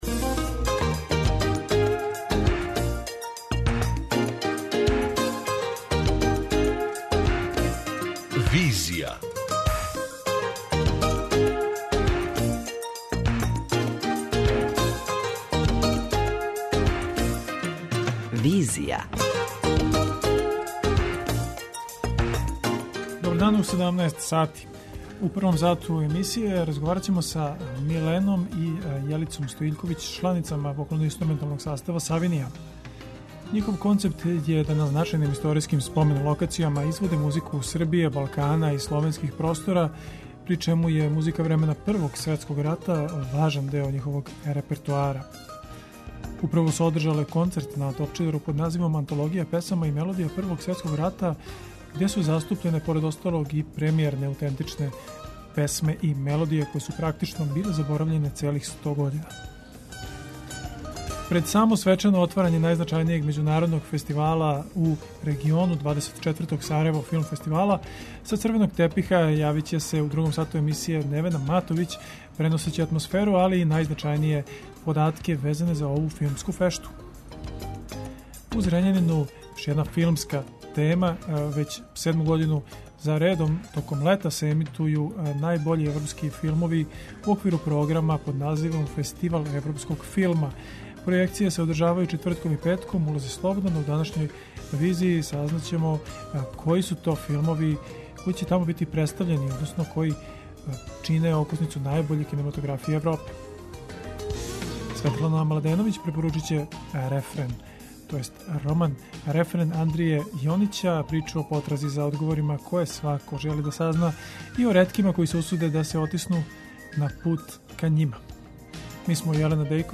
преузми : 55.69 MB Визија Autor: Београд 202 Социо-културолошки магазин, који прати савремене друштвене феномене.